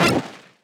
Sound effect of Fireball in Super Mario 3D World.
SM3DW_Fireball.oga